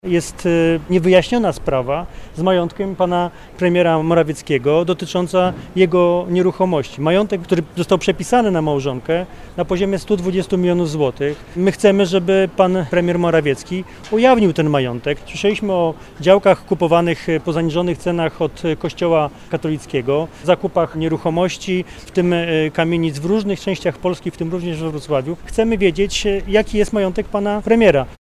-Po pierwsze, chcemy wiedzieć jaki jest majątek pana Premiera. -mówi Michał Jaros, szef dolnośląskich struktur Platformy Obywatelskiej.